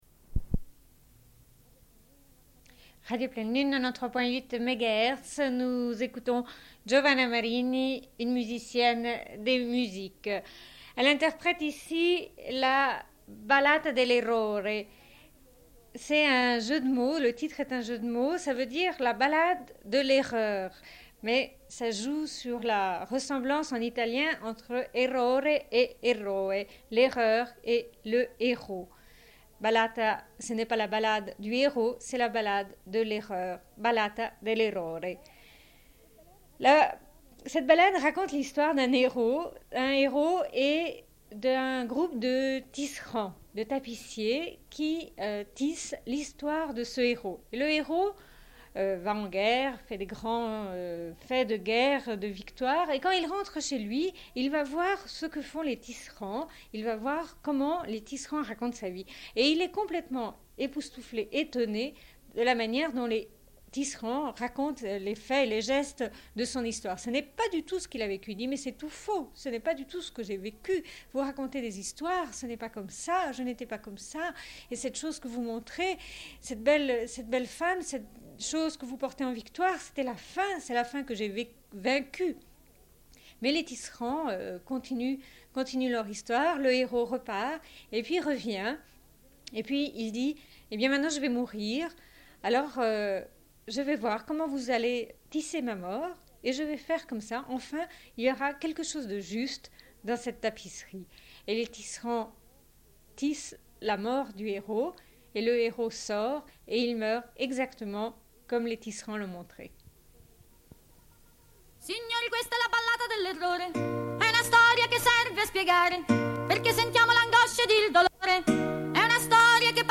Suite de l'émission dédiée à Giovanna Marini. Diffusion d'un entretien mené avec elle à l'occasion d'un concert à Genève puis écoute de musique.